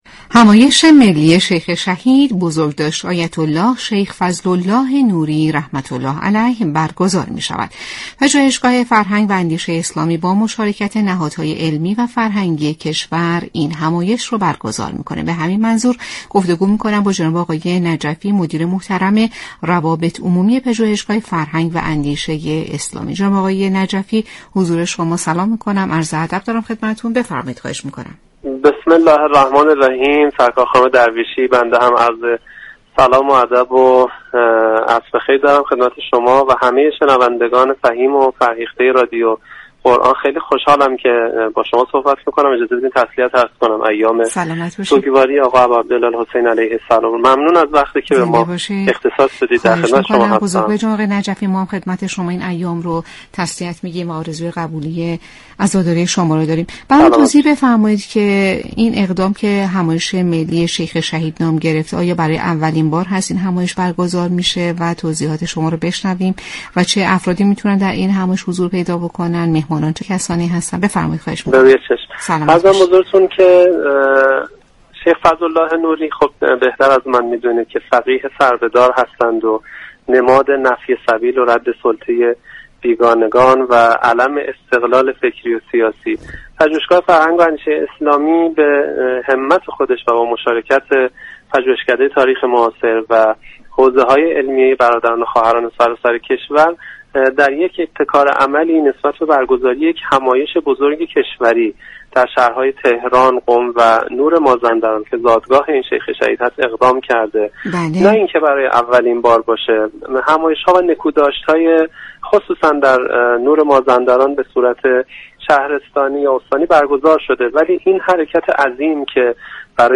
در گفت‌وگو با برنامه والعصر رادیو قرآن
گفتنی است؛ برنامه عصرگاهی "والعصر" كه با رویكرد اطلاع رسانی یكشنبه تا چهارشنبه ی هر هفته بصورت زنده از رادیو قرآن پخش می شود.